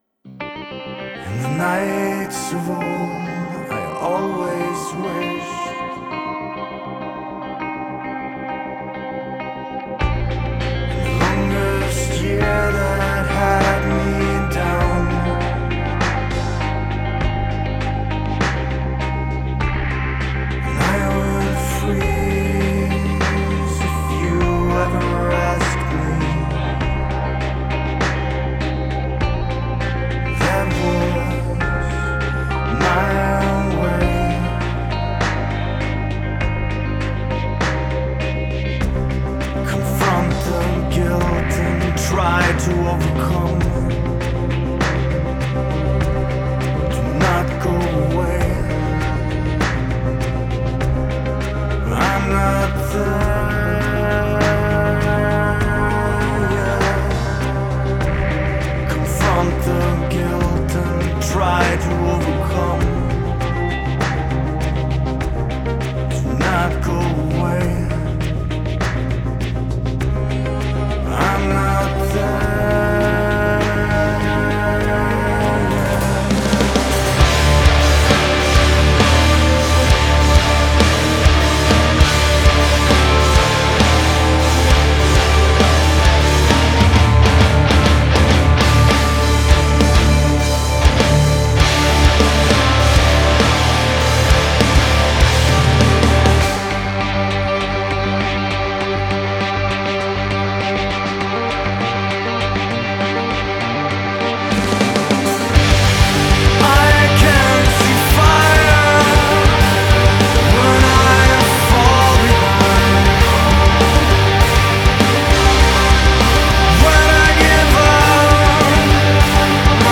progressive metal